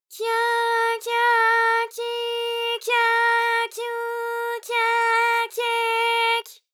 ALYS-DB-001-JPN - First Japanese UTAU vocal library of ALYS.
kya_kya_kyi_kya_kyu_kya_kye_ky.wav